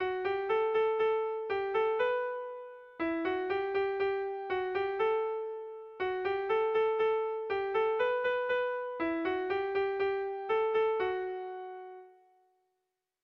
Irrizkoa
ABAB